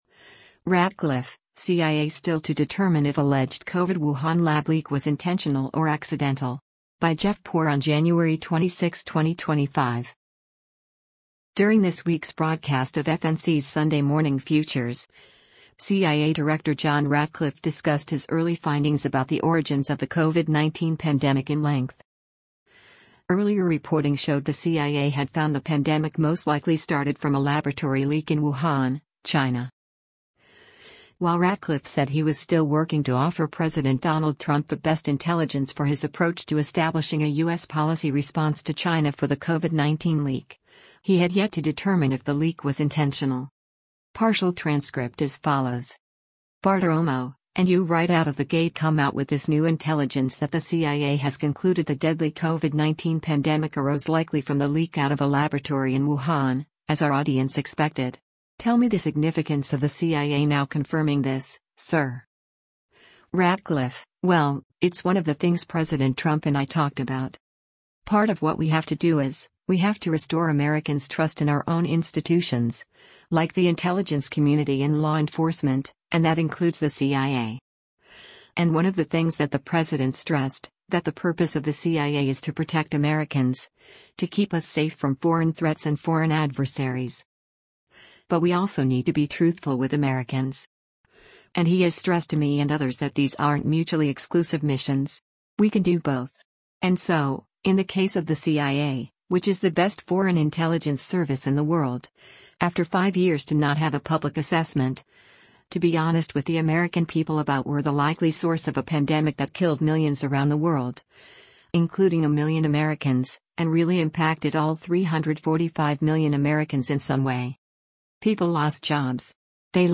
During this week’s broadcast of FNC’s “Sunday Morning Futures,” CIA director John Ratcliffe discussed his early findings about the origins of the COVID-19 pandemic in length.